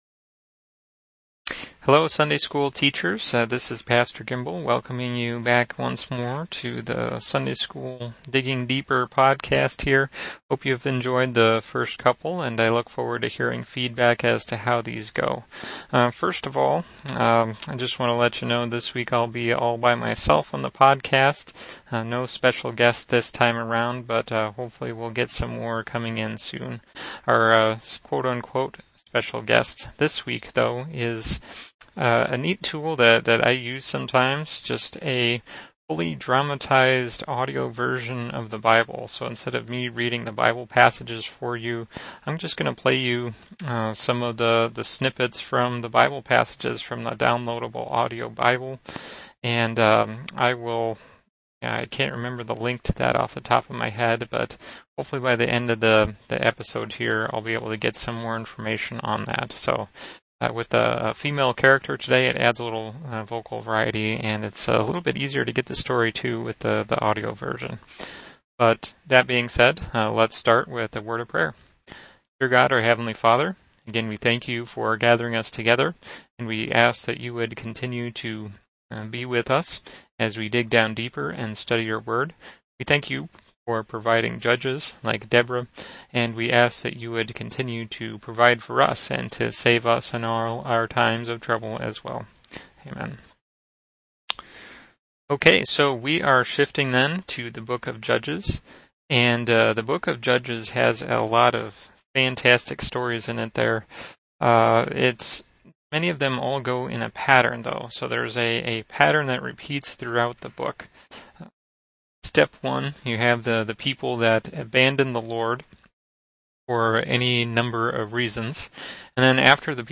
The lesson is Deborah and the Bible passage is Judges 4-5. Our special “guest” for this episode is the dramatized ESV audio Bible, available for free through Faith Comes By Hearing.